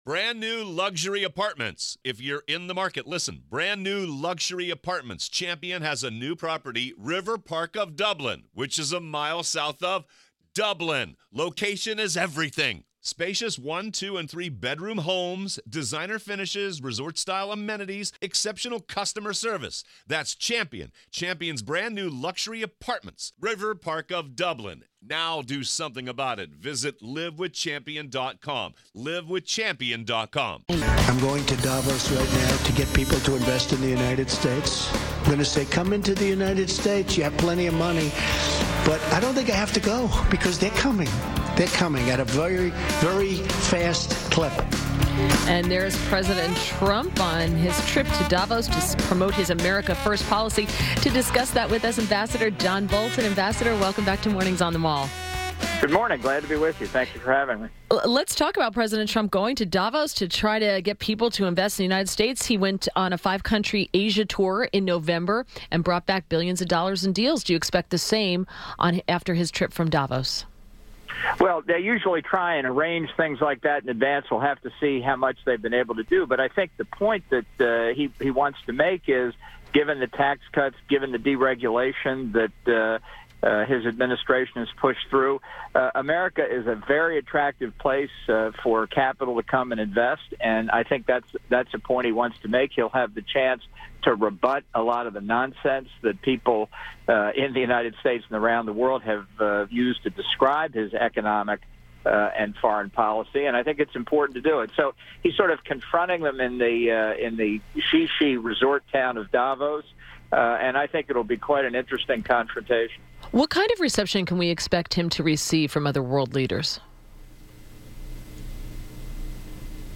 WMAL Interview - AMB. JOHN BOLTON - 01.25.18
INTERVIEW - AMBASSADOR JOHN BOLTON - Former UN Ambassador --- discussed President Trump in Davos and NBC kissing up to North Korea.